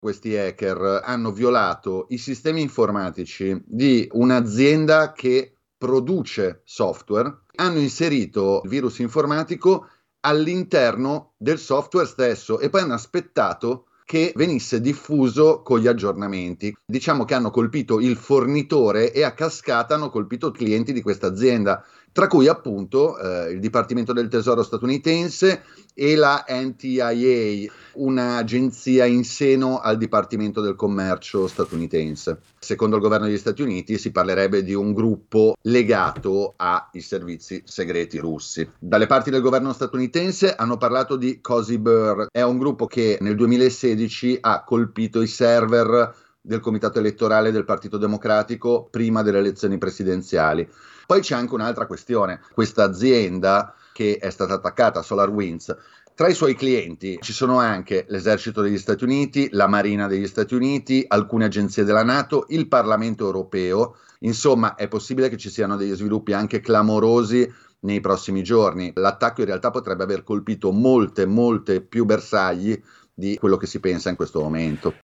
L’azienda ha diffuso un comunicato che parla di un problema interno, smentendo implicitamente l’ipotesi di un legame con la notizia arrivata poche ore prima: quella di un attacco hacker che ha colpito il governo degli Stati Uniti. Su questa operazione sentiamo il nostro collaboratore